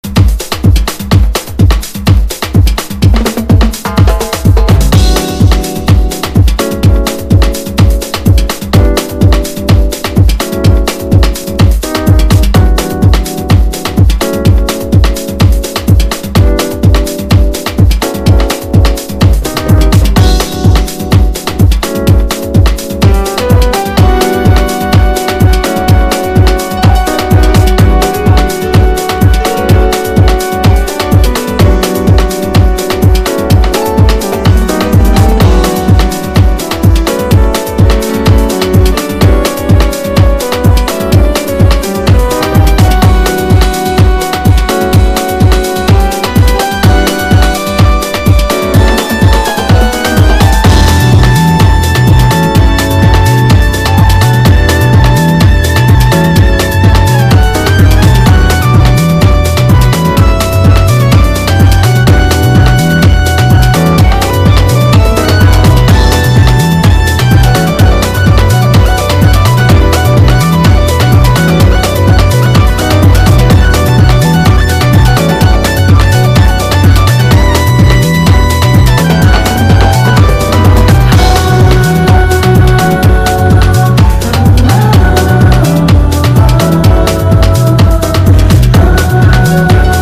DEEP HOUSE / EARLY HOUSE# CROSSOVER / LOUNGE